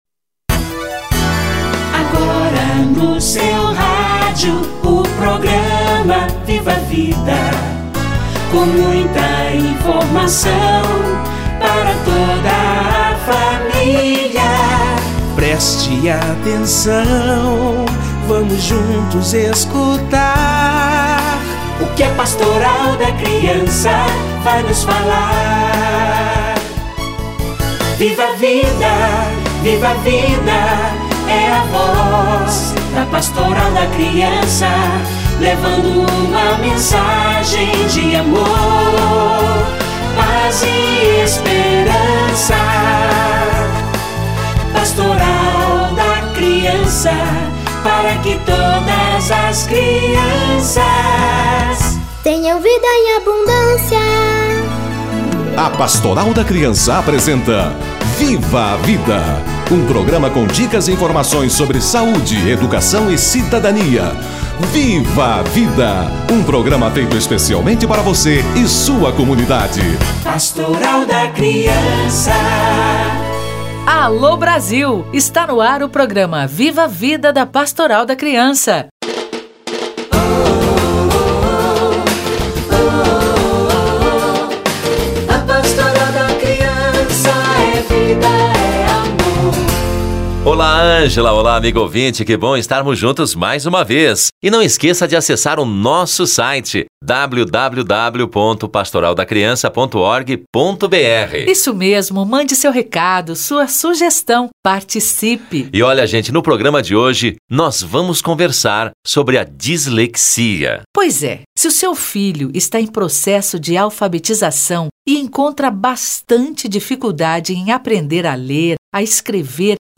Dislexia - Entrevista